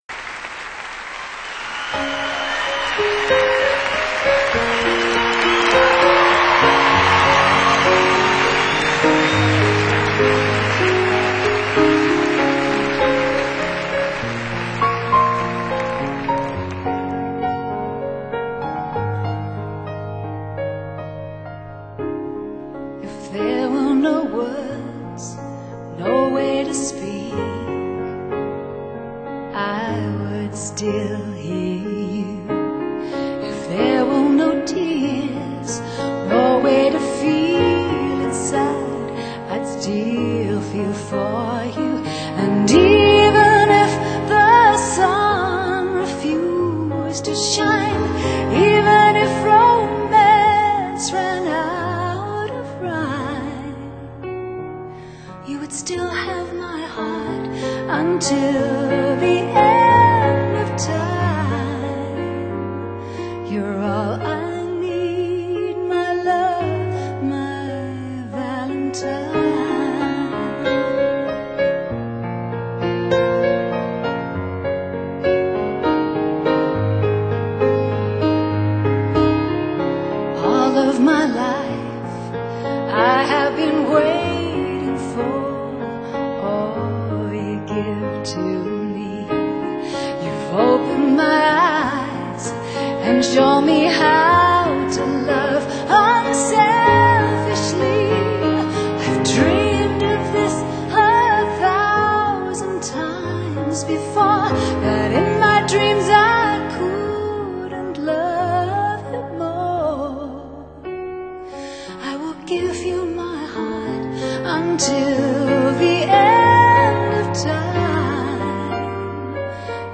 recorded live in Salt Lake City in March, 2000.